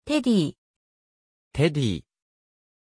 Pronunciation of Teddie
pronunciation-teddie-ja.mp3